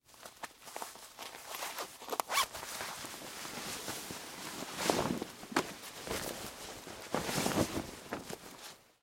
Звуки штанов
Шорох снимаемых брюк